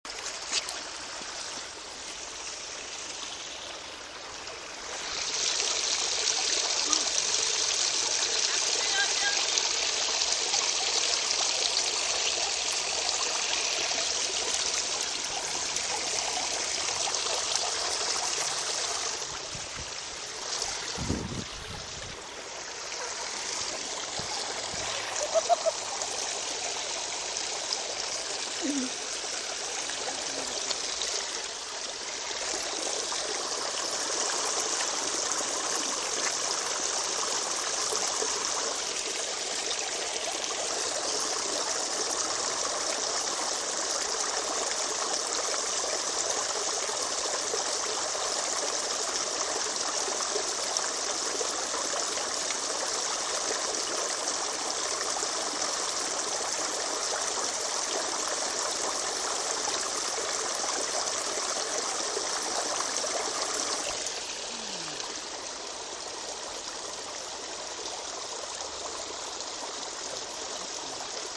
Fontaine, motos, voitures